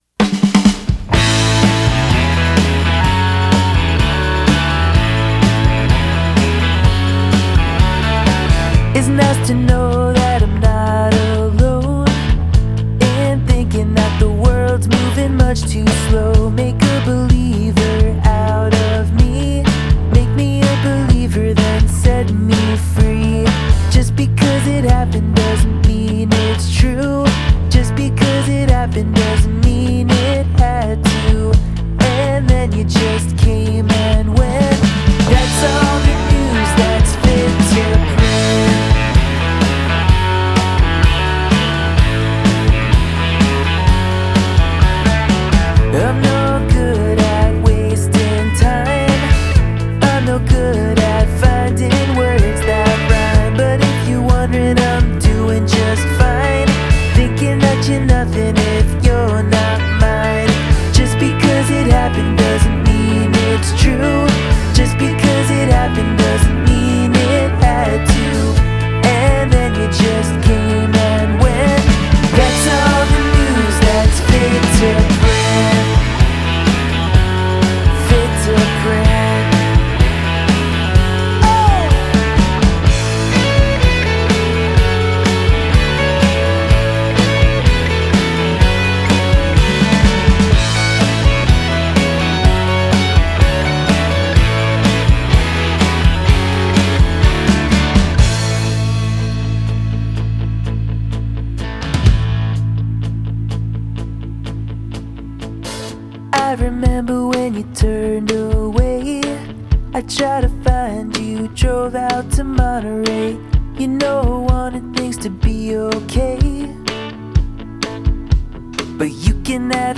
in a bright, breezy and melodic way